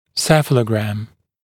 [ˈsefələgræm][ˈсэфэлэгрэм]цефалограмма (т.е. телерентгенограмма (ТРГ) головы в боковой проекции при соблюдении соответствующих требований)